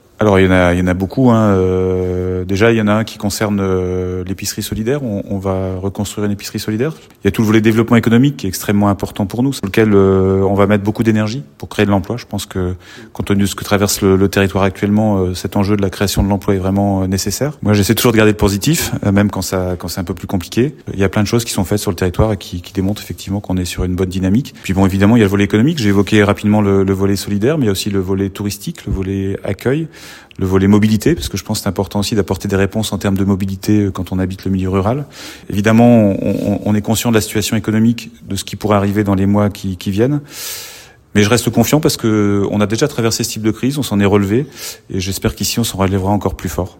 Lundi soir la salle Léo Lagrange de Lumbres accueillait les vœux de la CCPL ( Communauté de Communes du Pays de Lumbres ) . L’occasion pour son Président Christian LEROY de faire un point sur l’année écoulée et les nombreux projets du territoire .